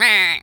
pgs/Assets/Audio/Animal_Impersonations/duck_quack_hurt_03.wav at master
duck_quack_hurt_03.wav